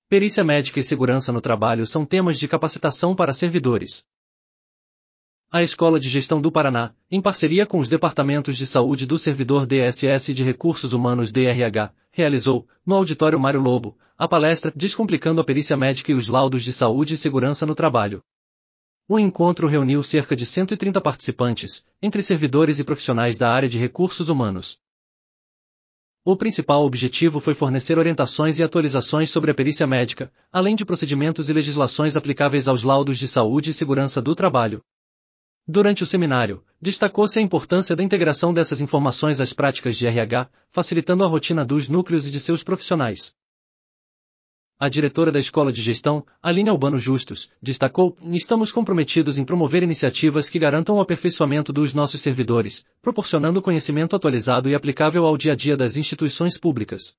audionoticia_evento_pericia_medica.mp3